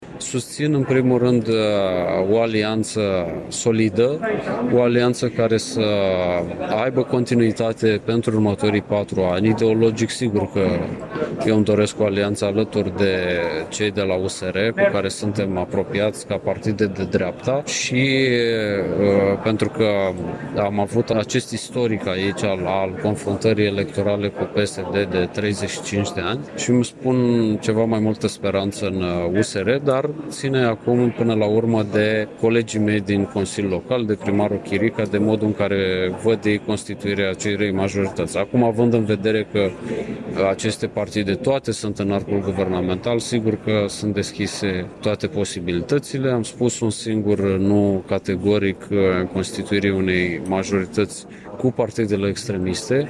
La rândul său deputatul PNL de Iași, Alexandru Muraru a declarat că se declară mai deschis unei alianțe cu USR, dar în niciun caz formării unei alianțe cu partidele extremiste.